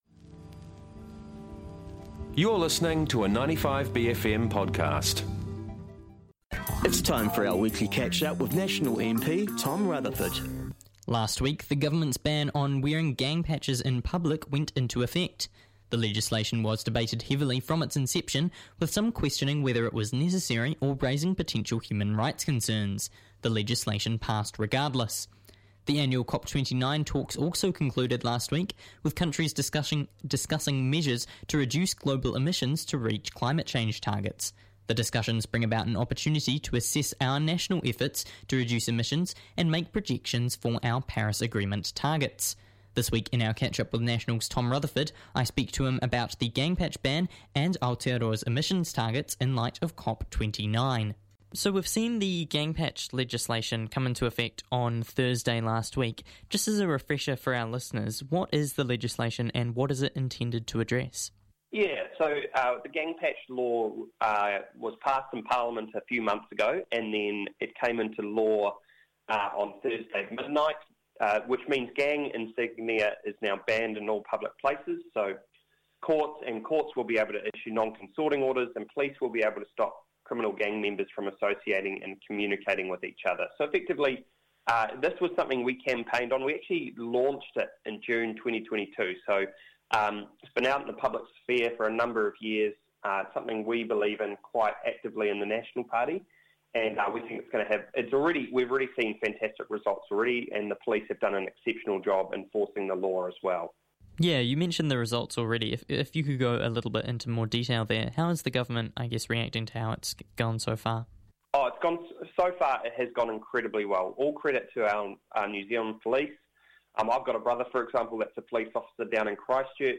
1 Guest Interview w/ SHIHAD: November 13, 2024